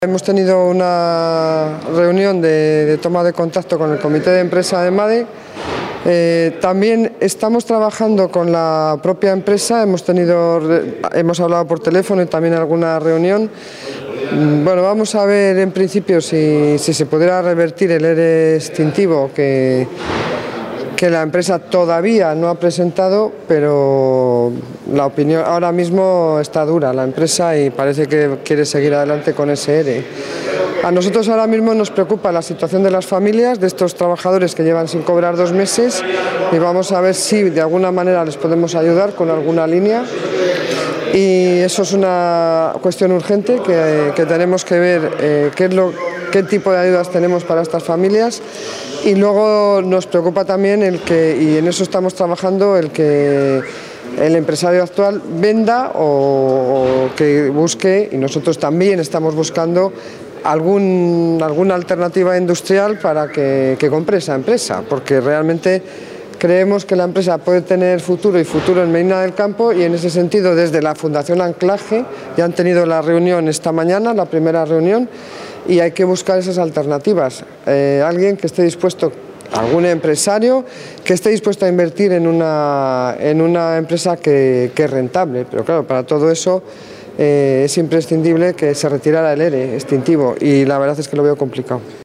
Declaraciones de la consejera de Economía y Hacienda.